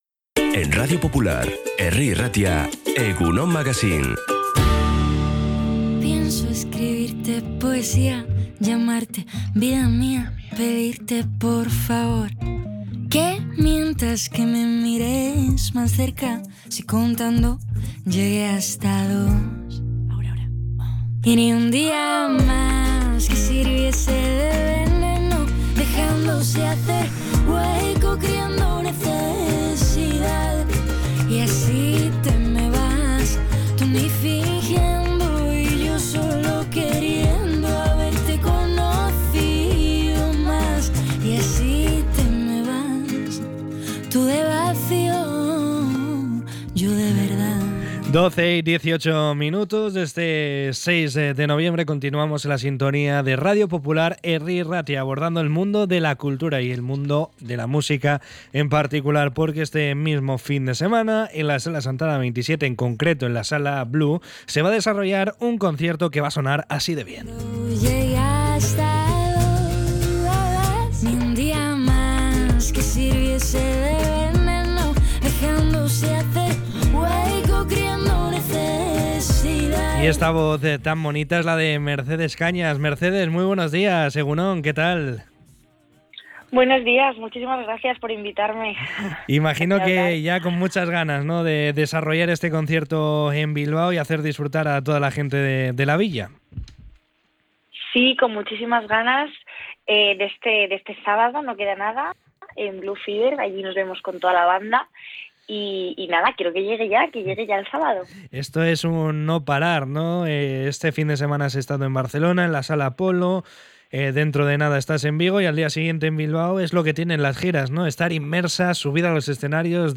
Entrevista con la cantautora madrileña